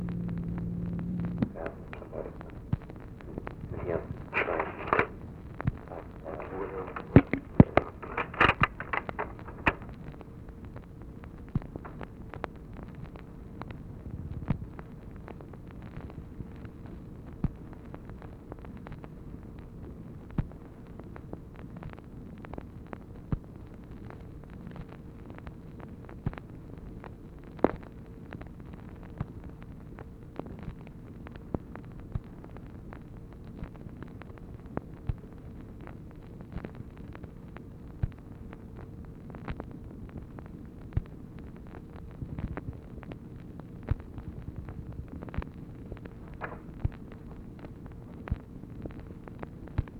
OFFICE CONVERSATION, January 6, 1964
Secret White House Tapes